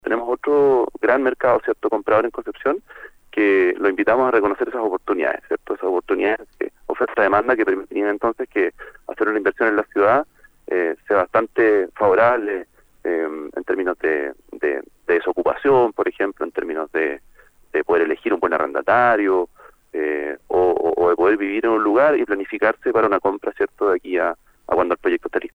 En entrevista con Nuestra Pauta